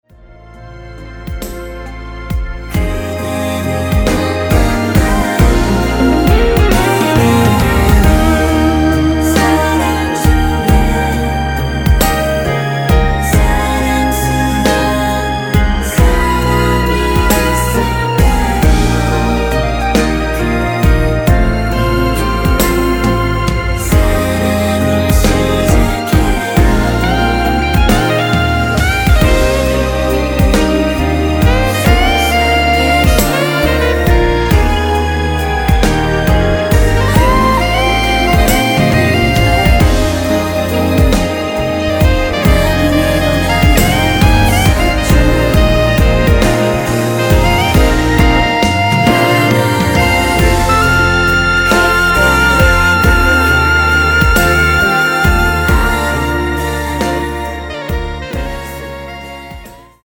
순수 코러스만 들어가 있으며 멤버들끼리 주고 받는 부분은 코러스가 아니라서 없습니다.(미리듣기 확인)
원키에서(+4)올린 멜로디와 코러스 포함된 MR입니다.(미리듣기 확인)
Eb
앞부분30초, 뒷부분30초씩 편집해서 올려 드리고 있습니다.
중간에 음이 끈어지고 다시 나오는 이유는